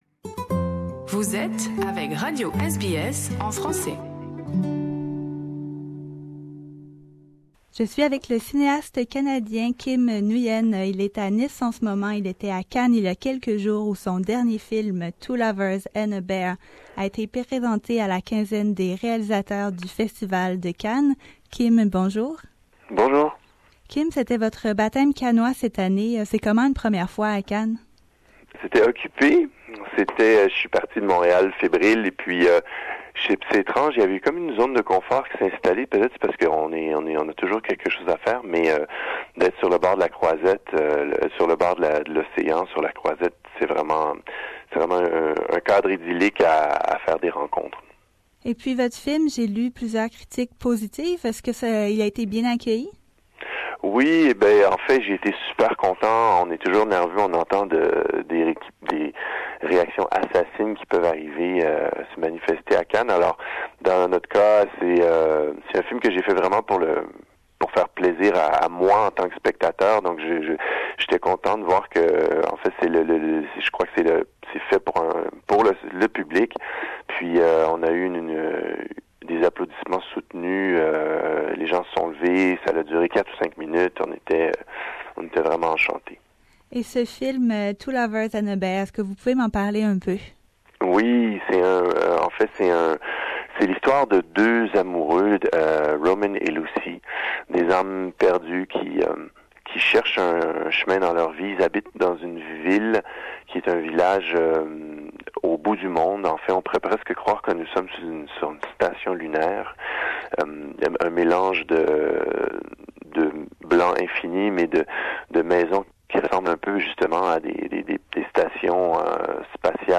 Interview with Canadian director Kim Nguyen